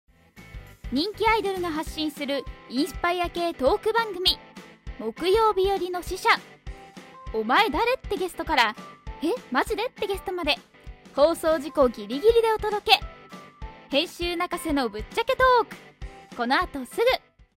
丁寧・高品質・リーズナブルなプロの女性ナレーターによるナレーション収録
音声ガイド（美術館）